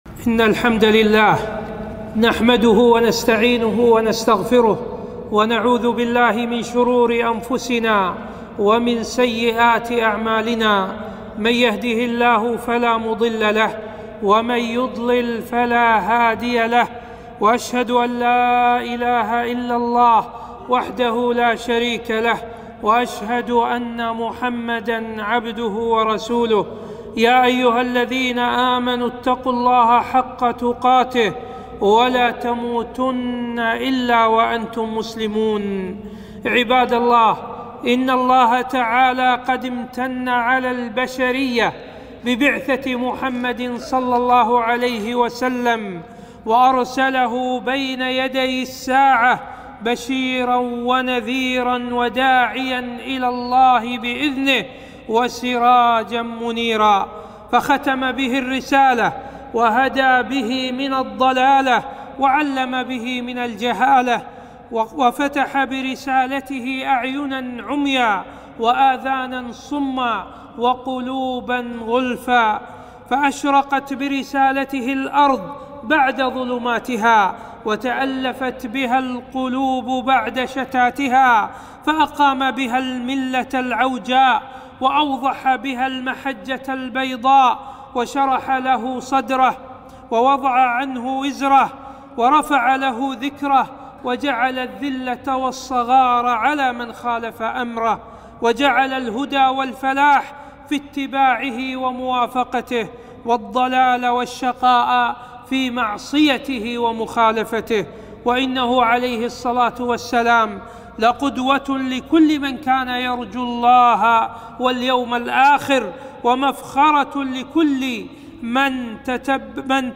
خطبة - نصرة النبي صلى الله عليه وسلم